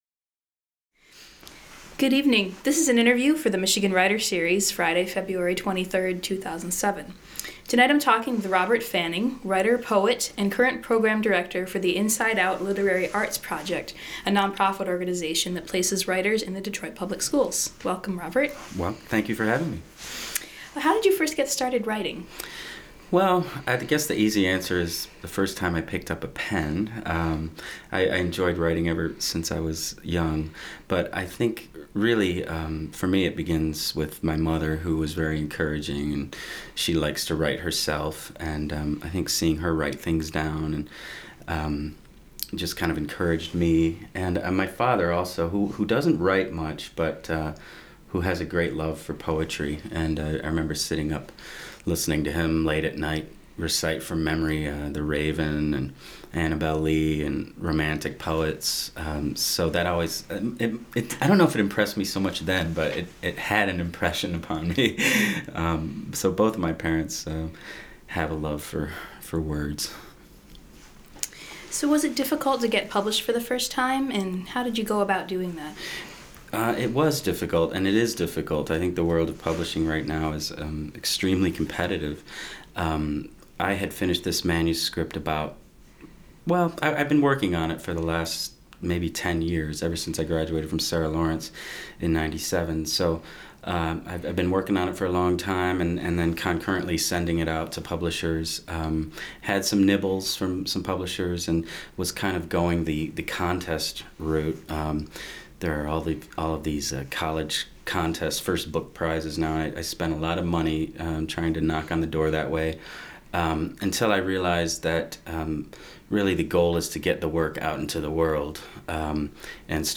Held in the MSU Main Library.